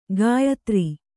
♪ gāyatri